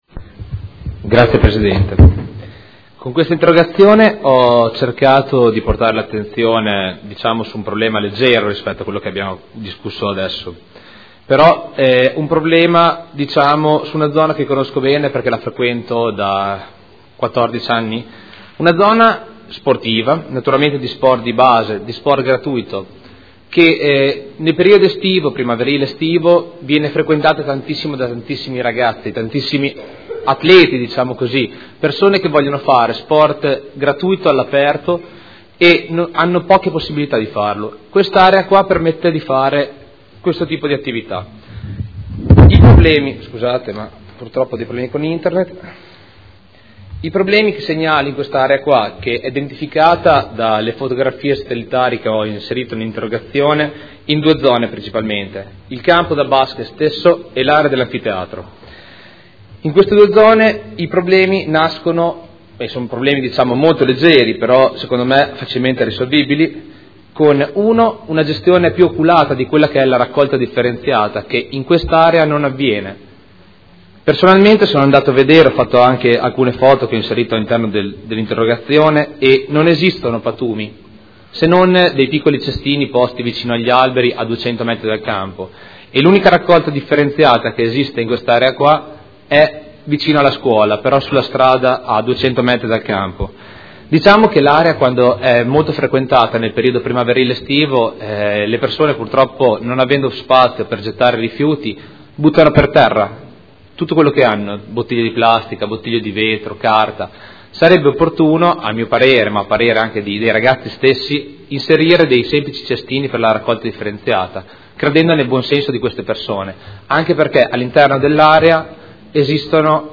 Seduta del 18 settembre. Interrogazione del consigliere Fantoni (M5S) avente per oggetto l'assetto dell'area "Campo Schiocchi"